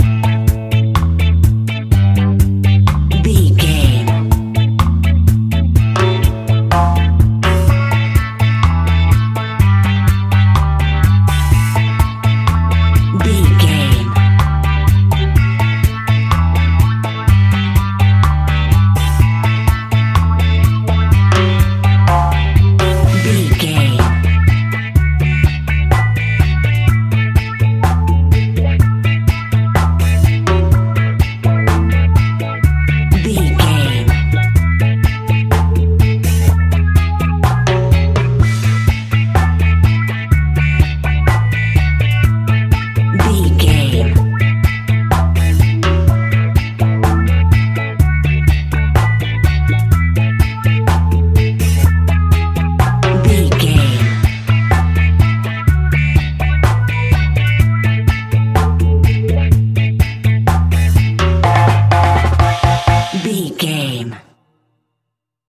Classic reggae music with that skank bounce reggae feeling.
Uplifting
Ionian/Major
instrumentals
laid back
chilled
off beat
drums
skank guitar
hammond organ
percussion
horns